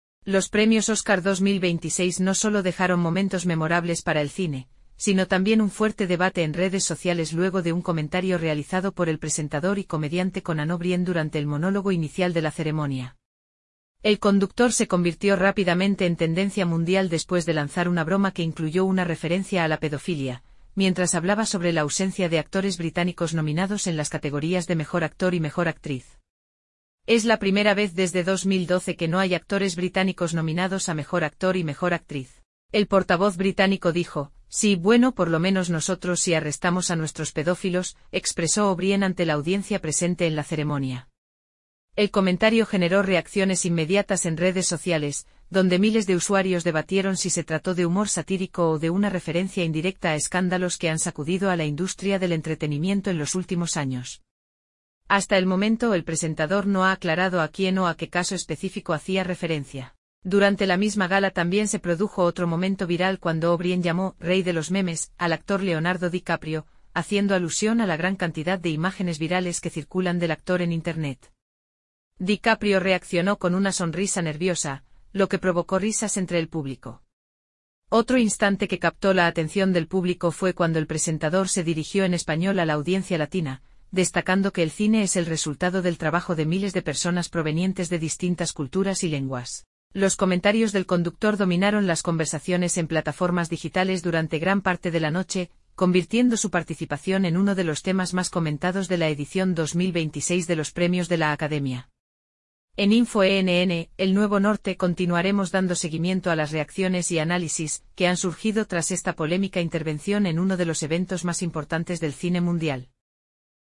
“Es la primera vez desde 2012 que no hay actores británicos nominados a Mejor Actor y Mejor Actriz. El portavoz británico dijo: ‘Sí, bueno, por lo menos nosotros sí arrestamos a nuestros pedófilos’”, expresó O’Brien ante la audiencia presente en la ceremonia.
DiCaprio reaccionó con una sonrisa nerviosa, lo que provocó risas entre el público.